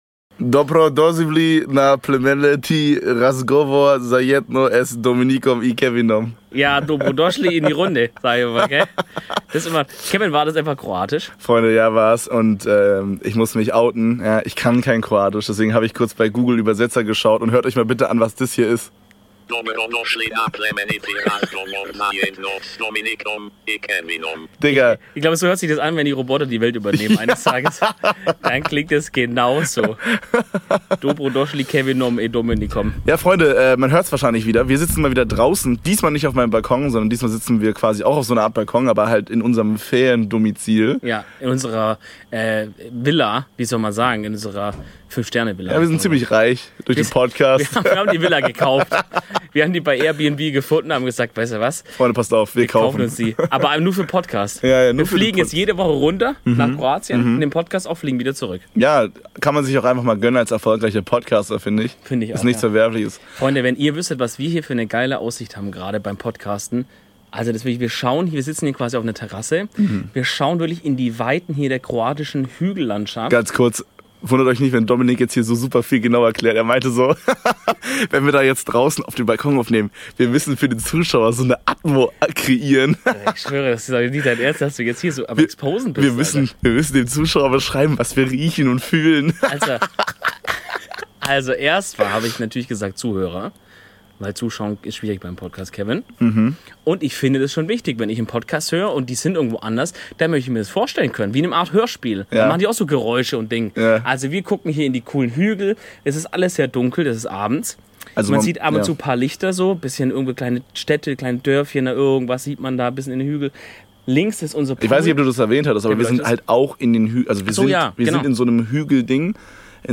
Ovu epizodu naša dva smeđa medvjeda snimila su izravno s terase u hrvatski praznik.